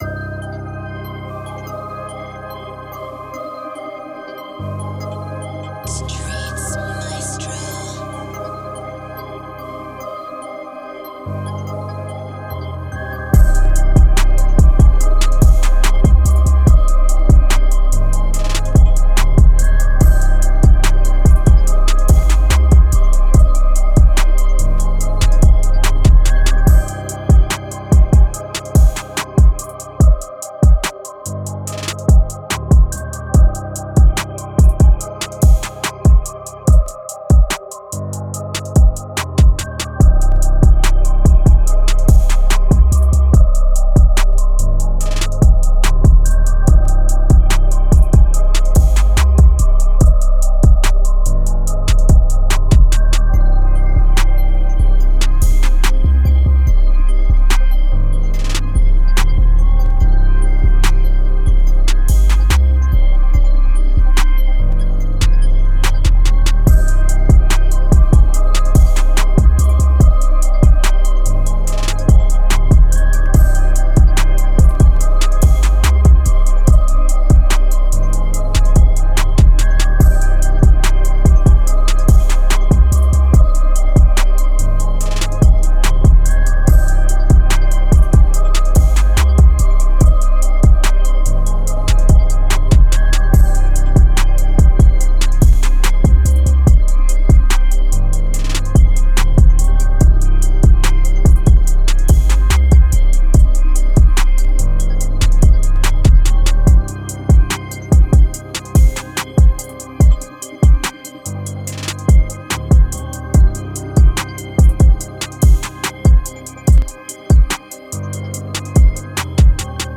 Moods: hard, dark, dramatic
Genre: Rap
Tempo: 144